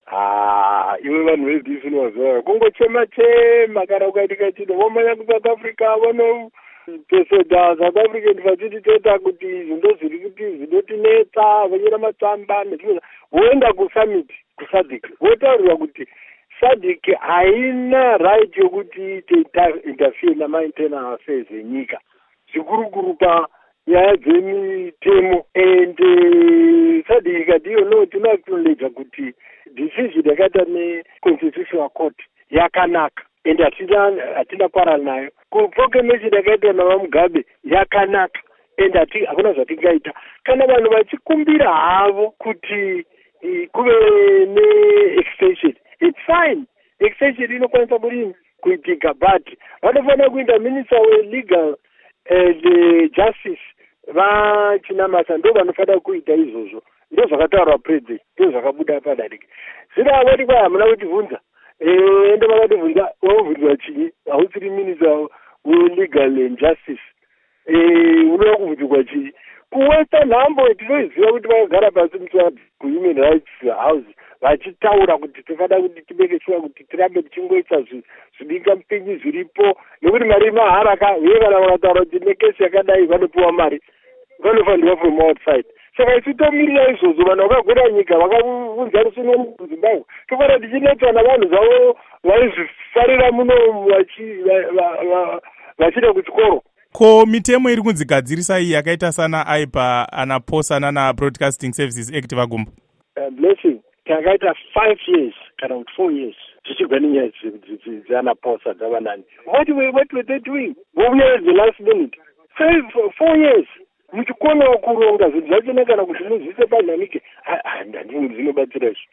Hurukuro naVaEric Matinenga